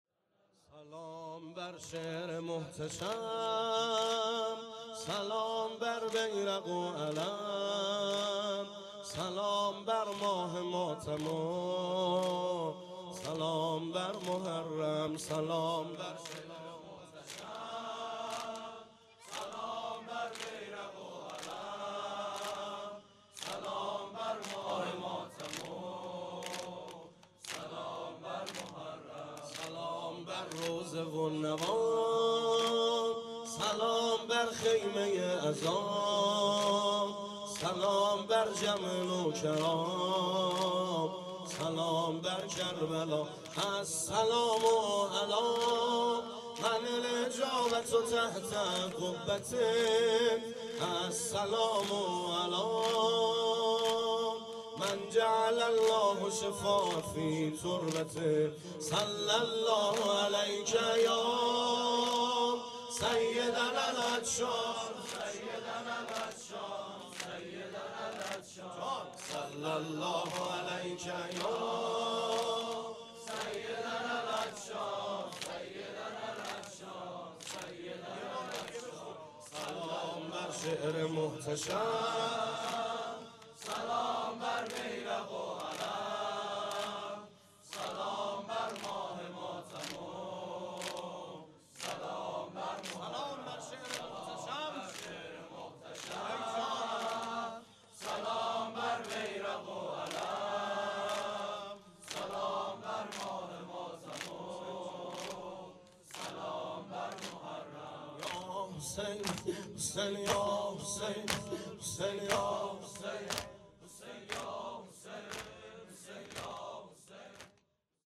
مراســم عـزادارى شـب ششم محرّم
گزارش صوتی شب ششم محرم 97 | هیأت محبان حضرت زهرا سلام الله علیها زاهدان